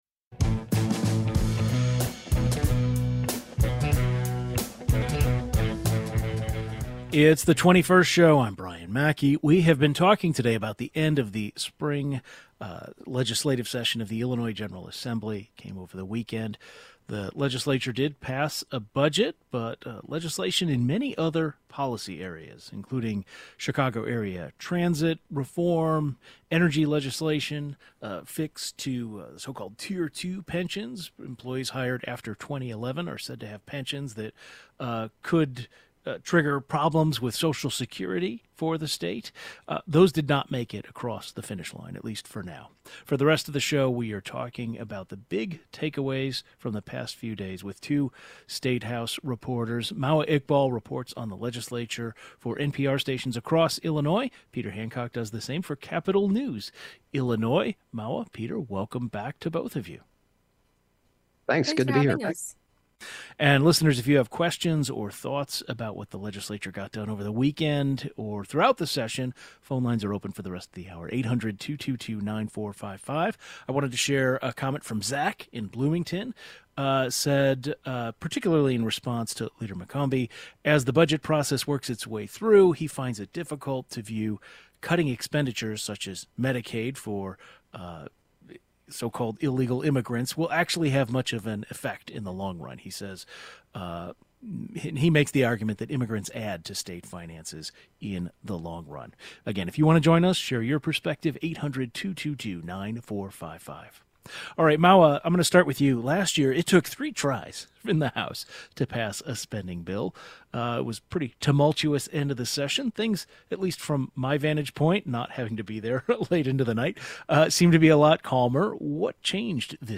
Two statehouse reporters discuss the big takeaways from the recently passed Illinois budget.
Two statehouse reporters break down important things to know about the budget including taxes, Medicaid, and what measure faced opposition.